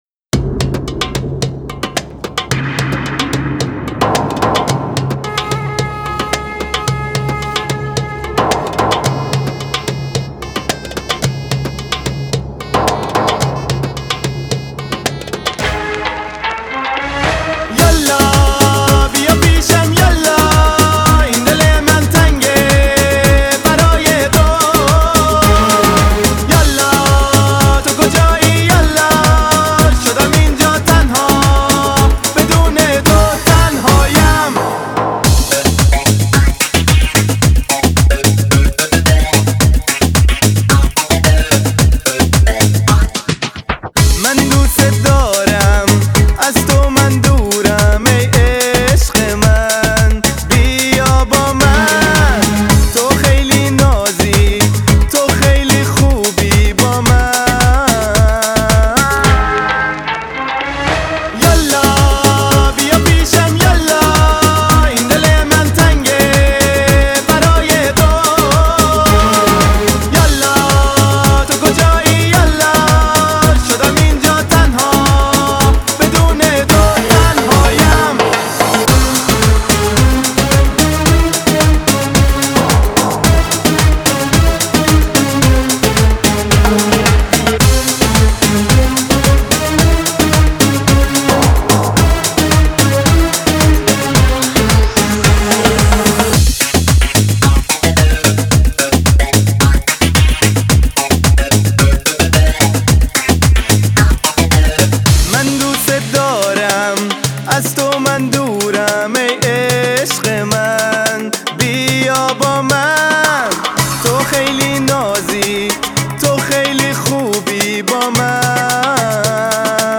Стиль: Pop